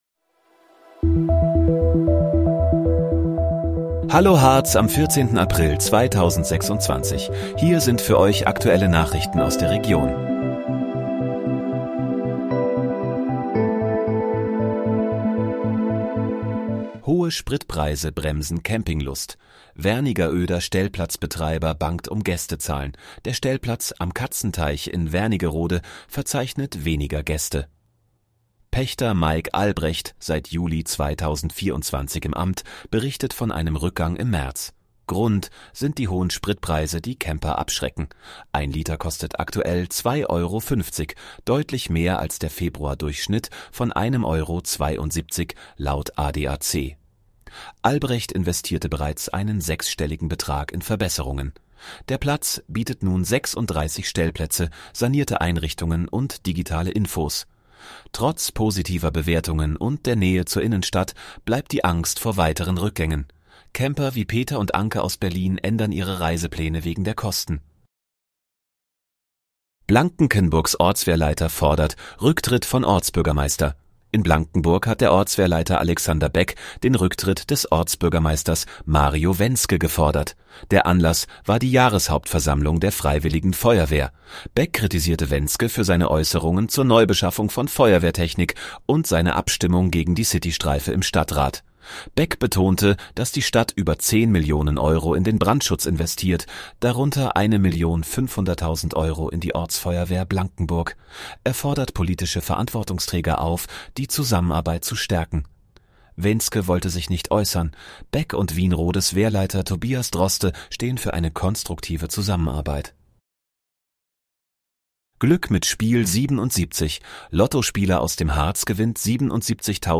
Hallo, Harz: Aktuelle Nachrichten vom 14.04.2026, erstellt mit KI-Unterstützung